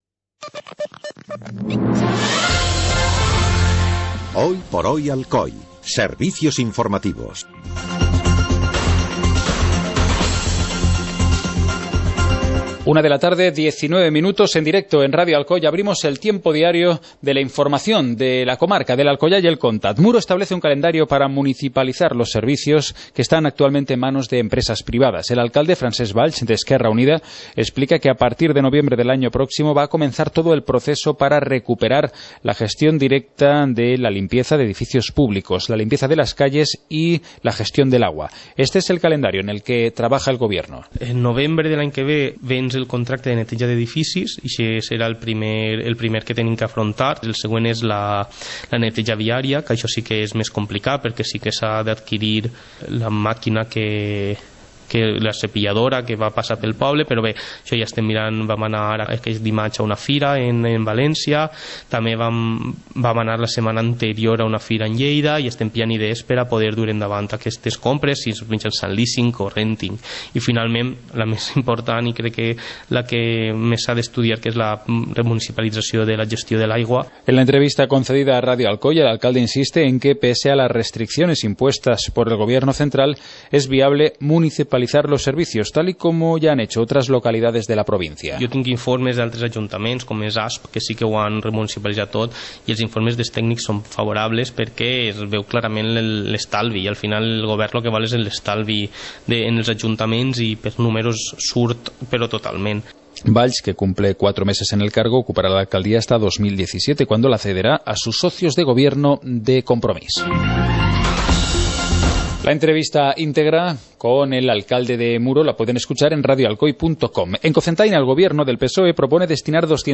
Informativo comarcal - jueves, 22 de octubre de 2015